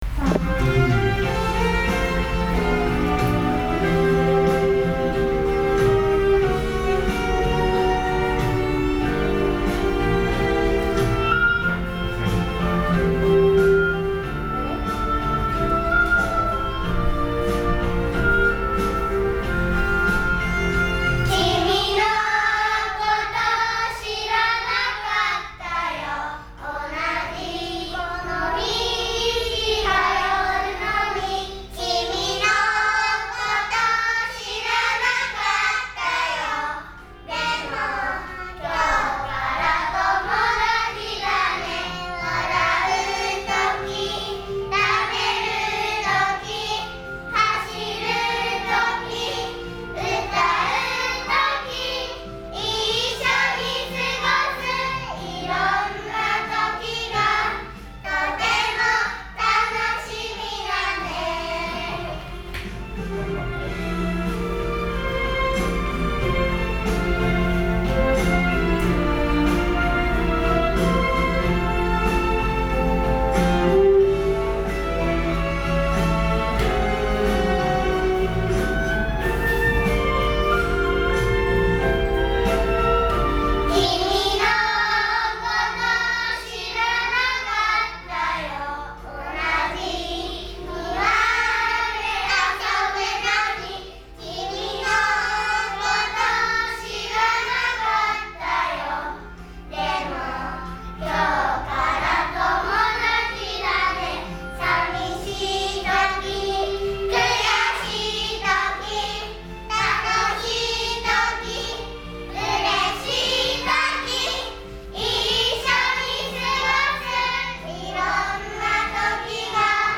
1年生の歌声「ともだち」
元気のよい歌声です。